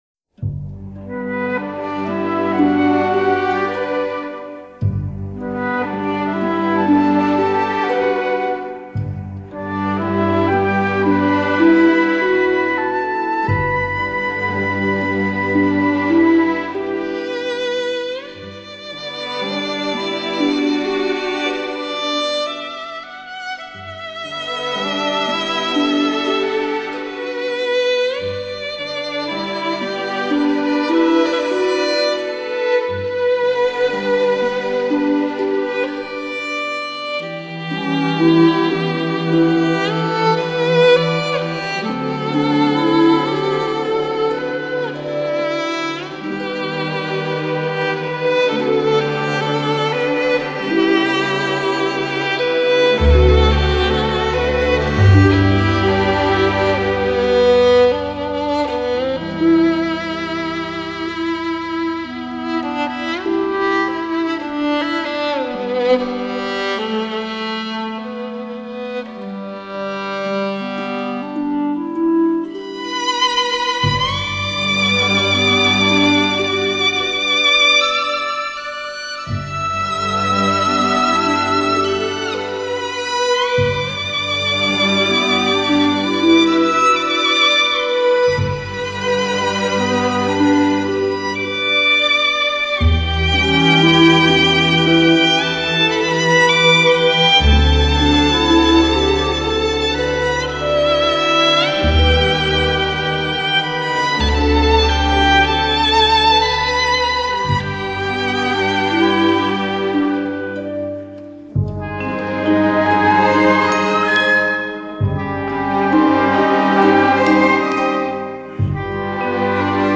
小提琴明亮动听的音色、炫耀的技艺与丰富的表现力，无不使听众倾倒。本辑所收的是极富中国风格特色的名曲。